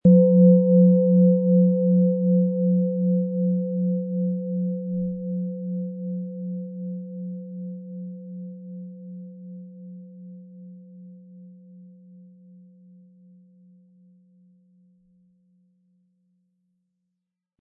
Planetenschale® Hindernisse meistern & Geborgen fühlen mit Wasser-Ton & Mond, Ø 14,7 cm, 320-400 Gramm inkl. Klöppel
• Mittlerer Ton: Mond
Im Audio-Player - Jetzt reinhören hören Sie genau den Original-Ton der angebotenen Schale.
Durch die überlieferte Herstellung hat diese Schale vielmehr diesen besonderen Ton und die innere Berührung der liebevollen Handfertigung.
MaterialBronze